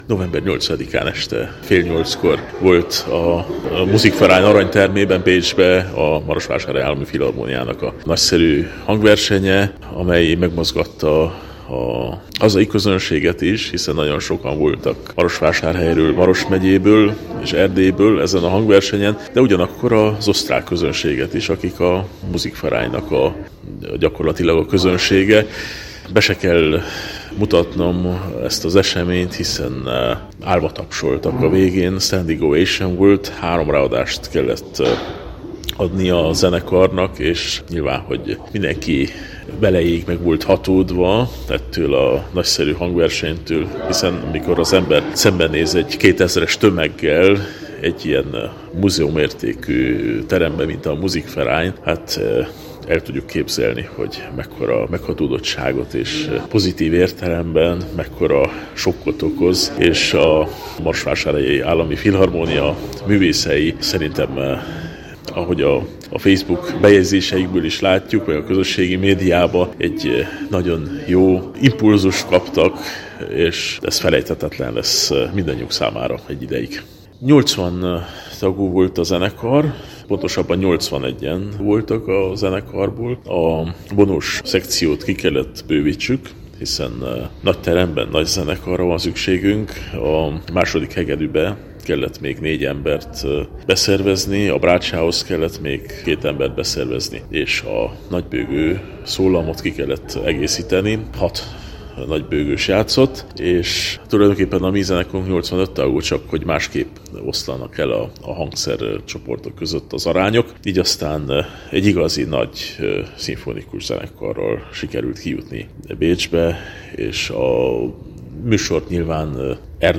kérdezte